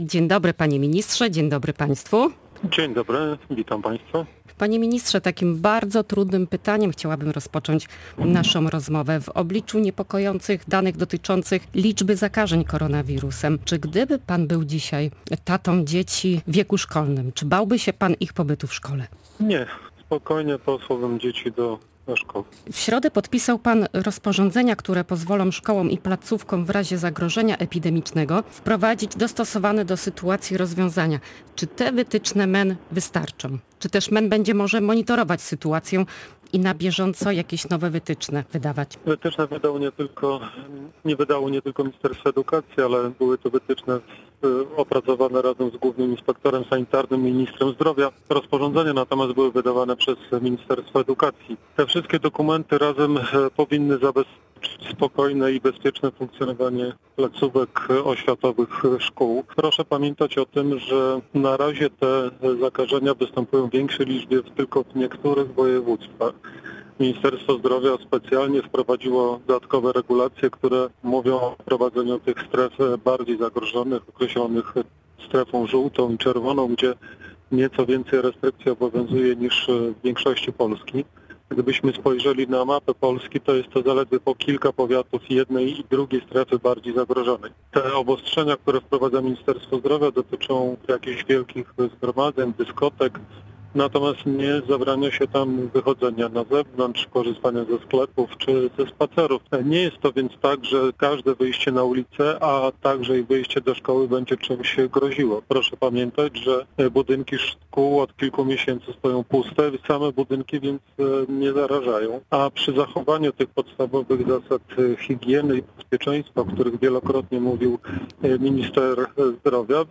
W większości szkół można wrócić do tradycyjnych zajęć i bezpośredniego kontaktu nauczyciela z uczniami - ocenił poranny Gość Radia Gdańsk